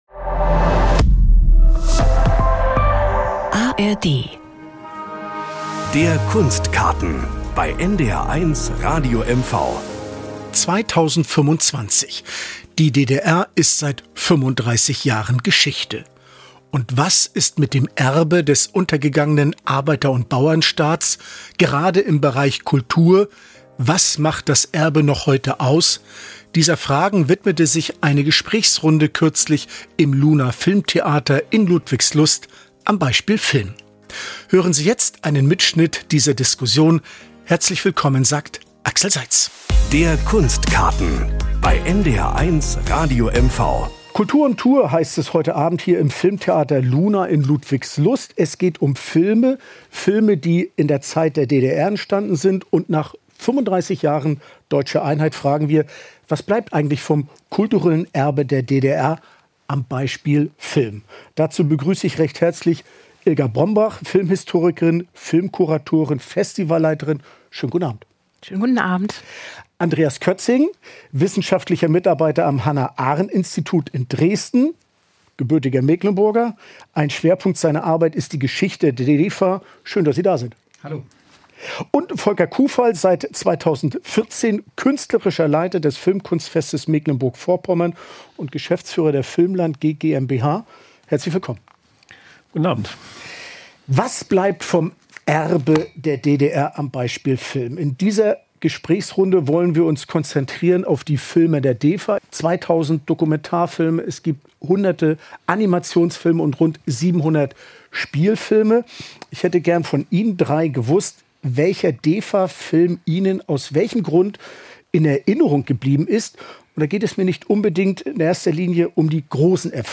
Beschreibung vor 6 Monaten 35 Jahre nach der Deutschen Einheit stellt der NDR in M-V die Frage: Was bleibt vom „Erbe der DDR“ in kultureller Hinsicht - am Beispiel Film? Im Filmtheater Luna in Ludwigslust spricht